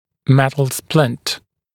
[‘metl splɪnt][‘мэтл сплинт]металлическая шина